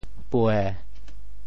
“焙”字用潮州话怎么说？
焙 部首拼音 部首 火 总笔划 12 部外笔划 8 普通话 bèi 潮州发音 潮州 buê7 文 中文解释 焙 <动> 微火烘烤 [bake;torrefy] 夜火焙茶香。